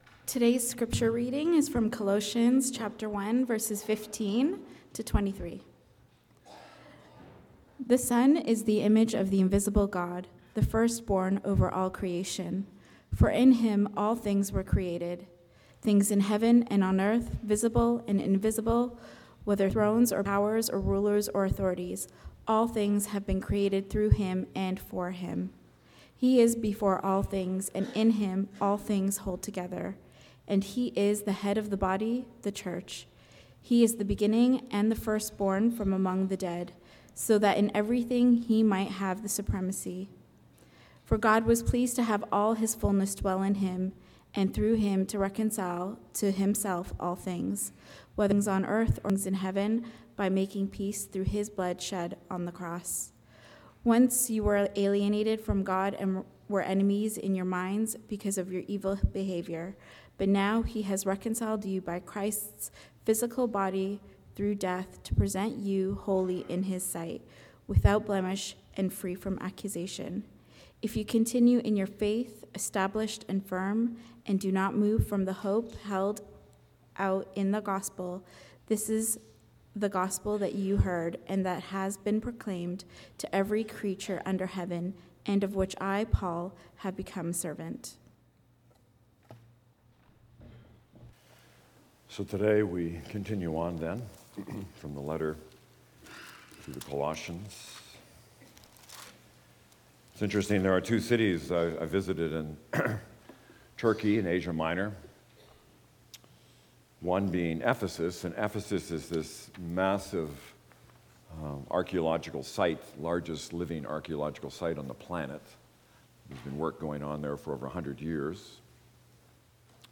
sermon_jan15.mp3